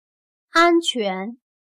安全/Ānquán/La seguridad